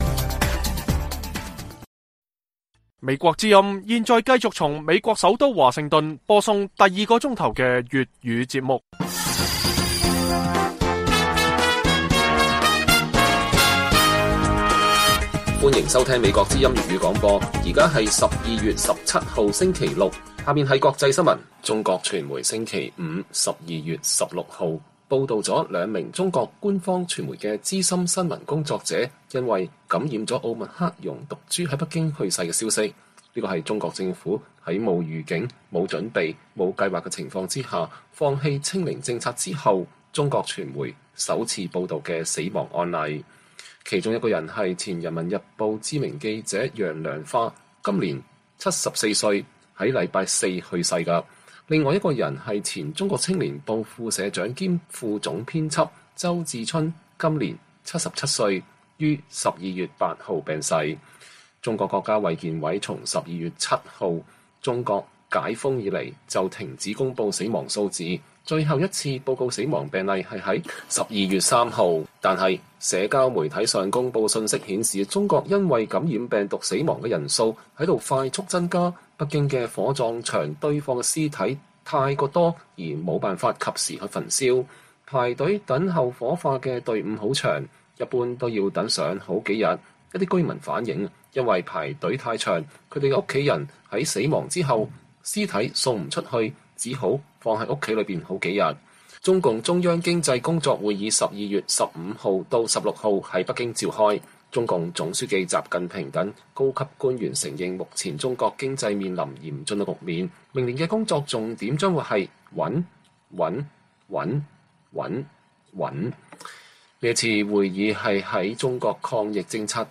粵語新聞 晚上10-11點 : 澳美部長級磋商會議邀日本參加聯合軍演，AUKUS是否應變JAUKUS?